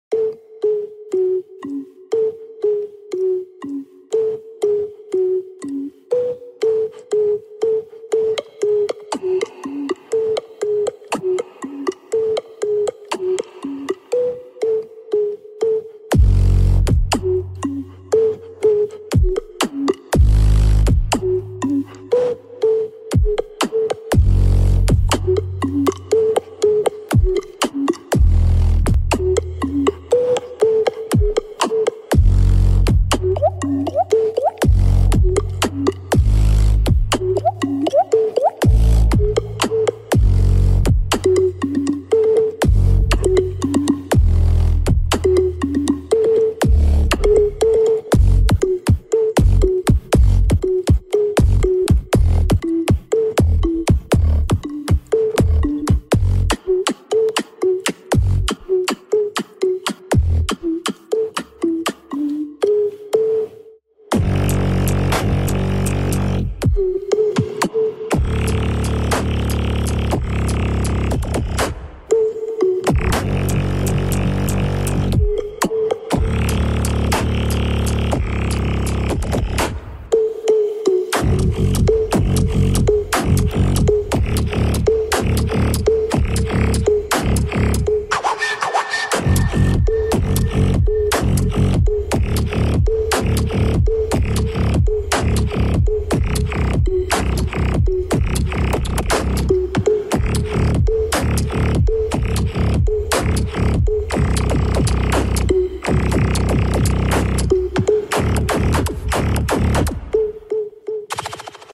The art of beatboxing